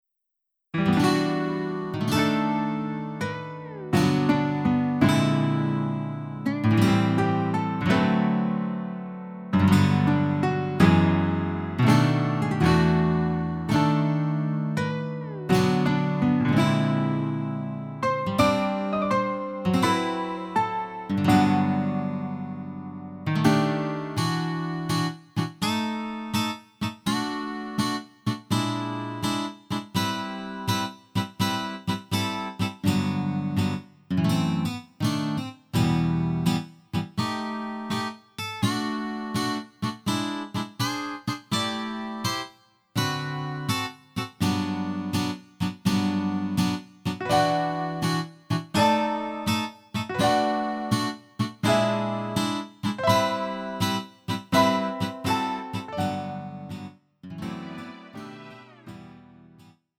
음정 -1키 3:33
장르 가요 구분